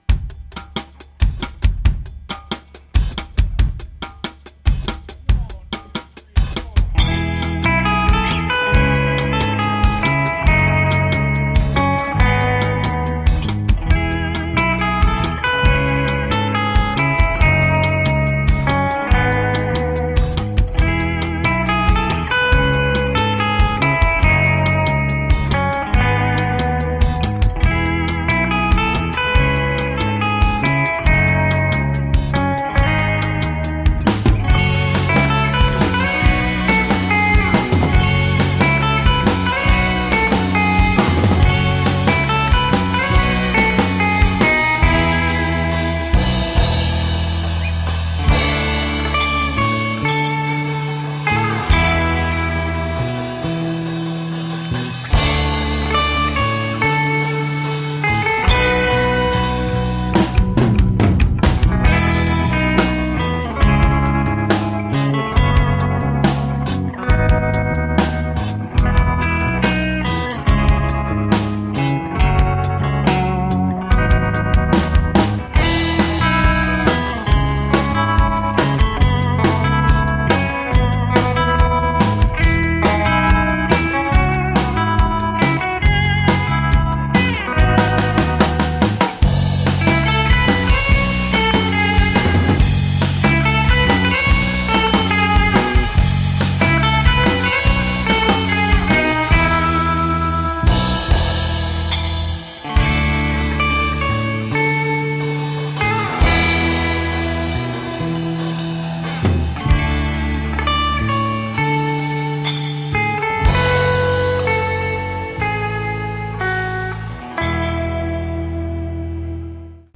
High-energy surf-inspired instrumental rock.